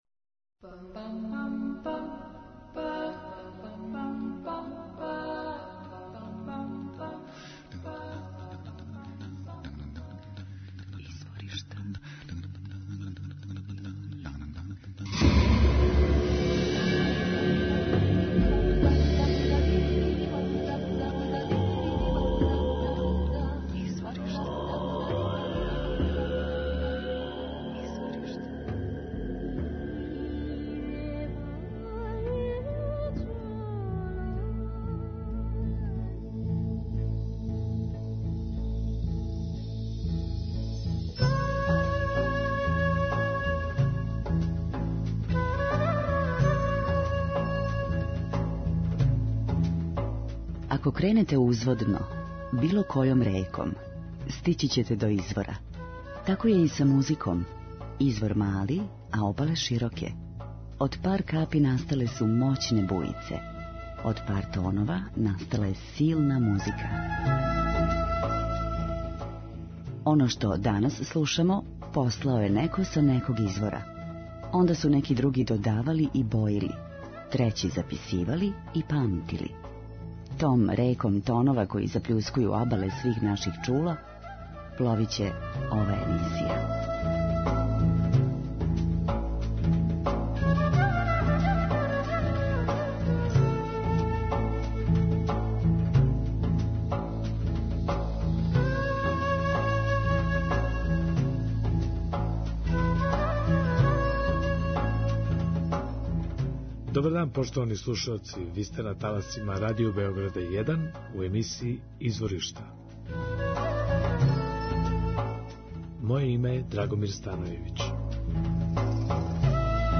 француско-алжирски певач.